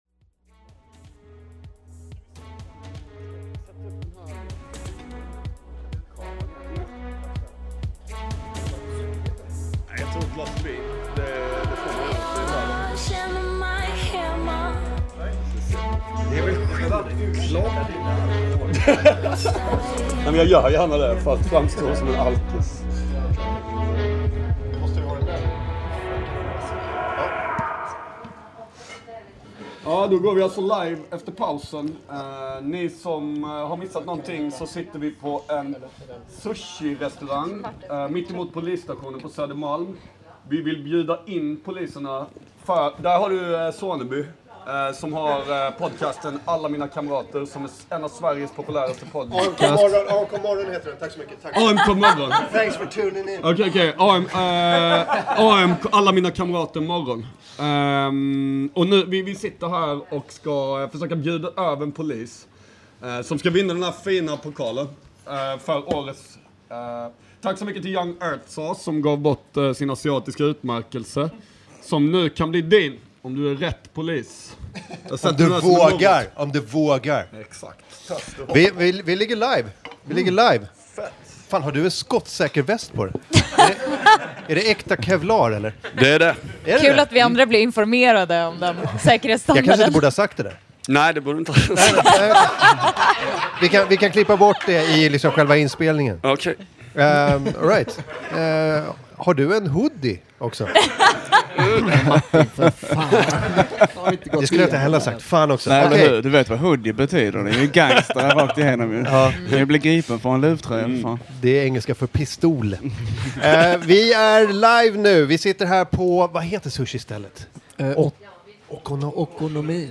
Sushirestaurangen Okonomi bara två dörrar bort från Feca, öppnar sina hjärtan och dörrar för oss och låter oss spela in hos dem.